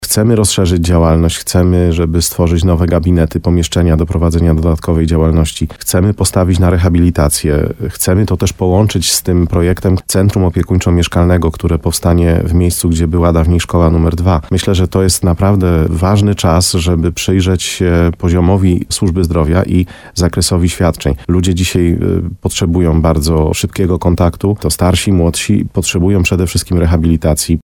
– Chcemy dostosować budynki do potrzeb mieszkańców – mówi wójt Mariusz Tarsa.